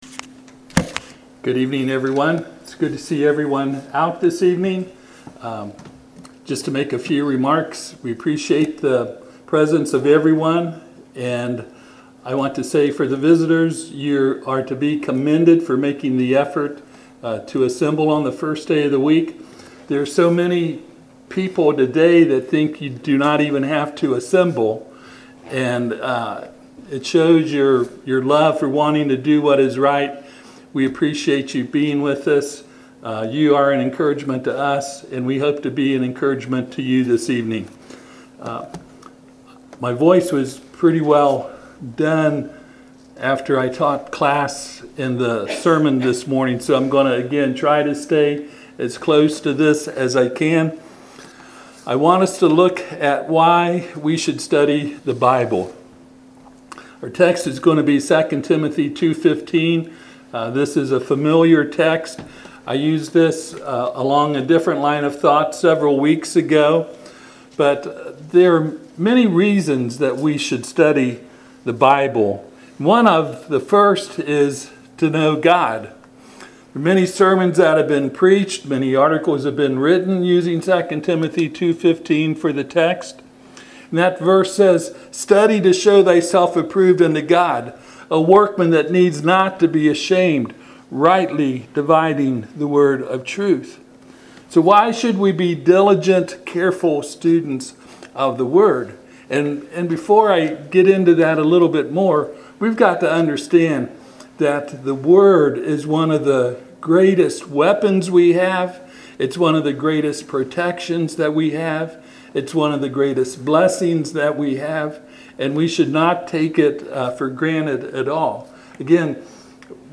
Passage: 2 Timothy 2:15 Service Type: Sunday PM